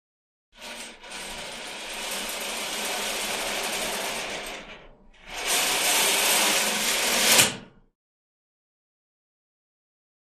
Hospital Curtains; Open / Close; Hospital Emergency Room Curtain; Open R-l / Close L-R, Medium Perspective.